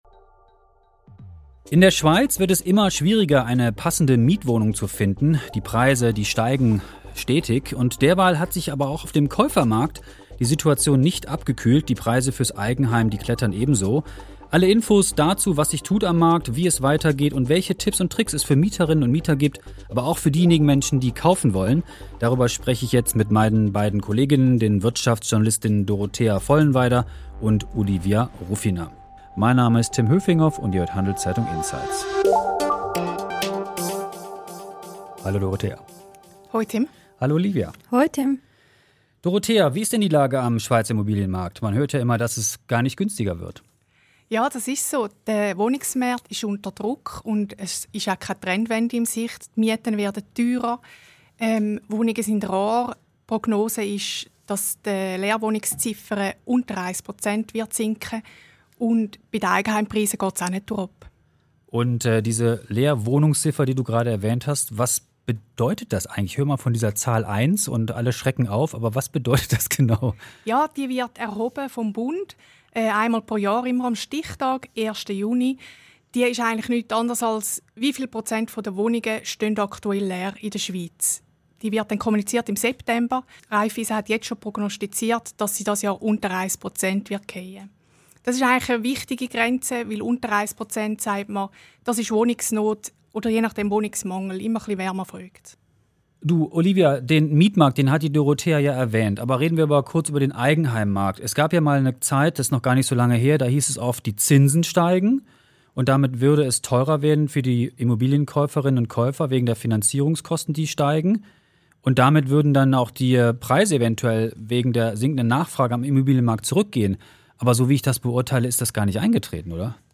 Darüber spricht Podcast-Host